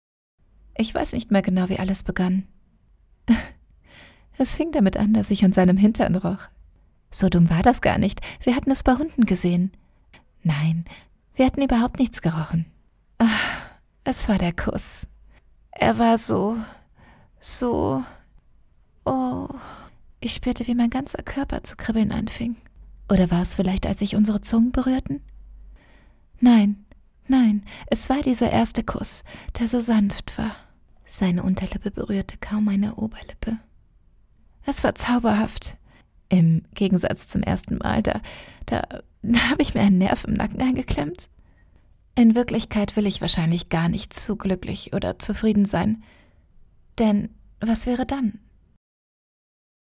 Sprechprobe: eLearning (Muttersprache):
german female voice over artist, actor